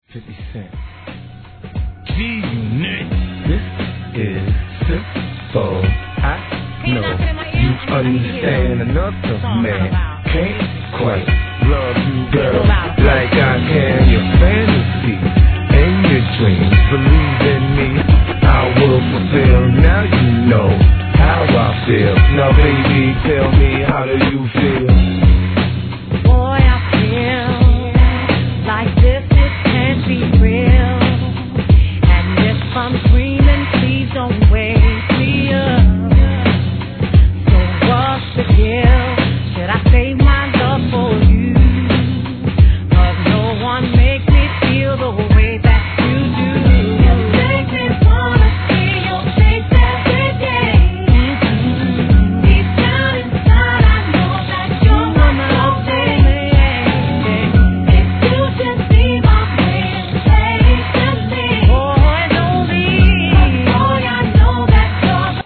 HIP HOP/R&B
REMIX物!